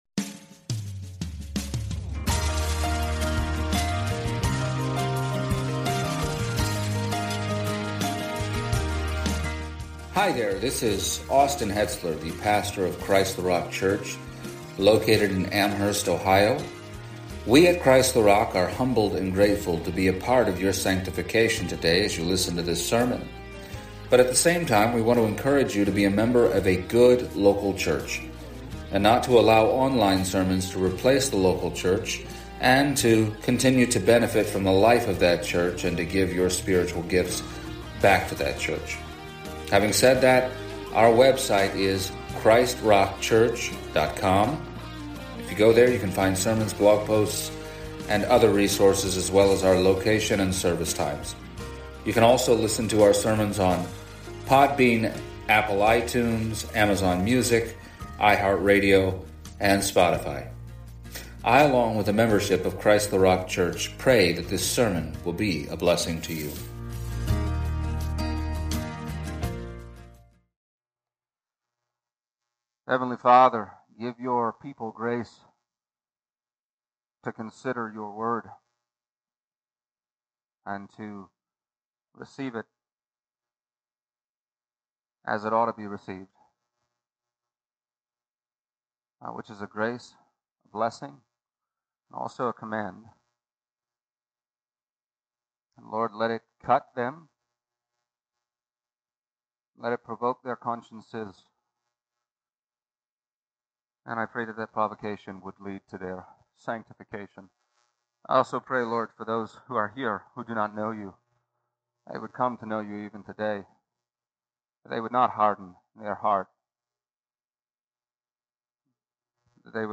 Passage: Acts 24:24-27 Service Type: Sunday Morning